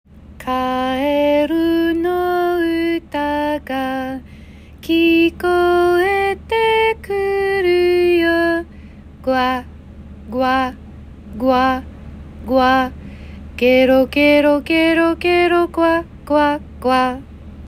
Audio Pronunciation